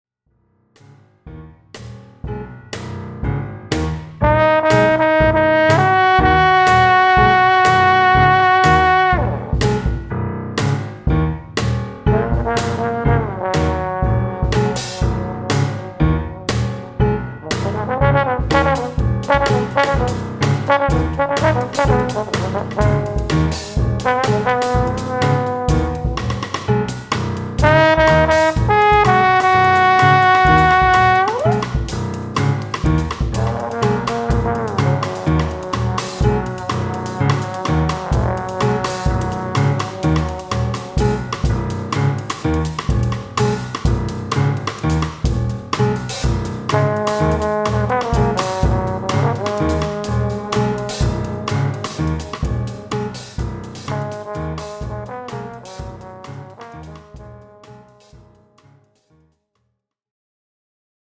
Klavier & Kompositionen
Stimme
Posaune
Schlagzeug